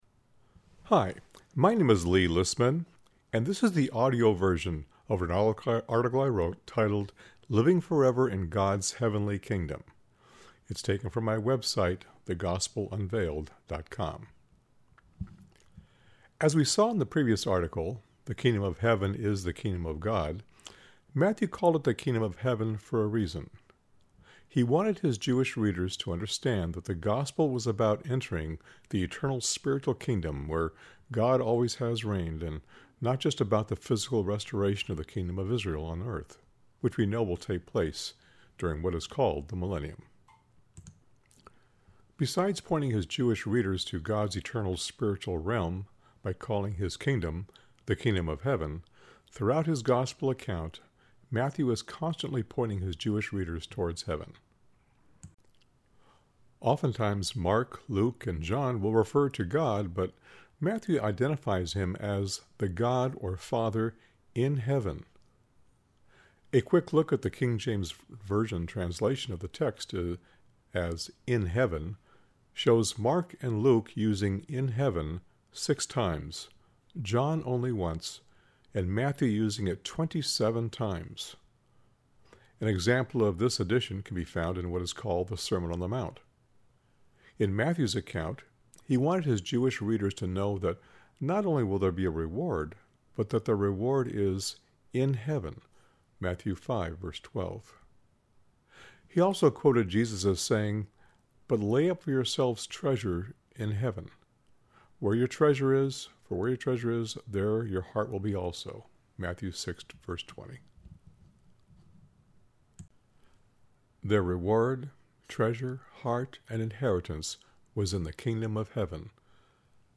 (Click Here if you wish to listen to the article read by myself)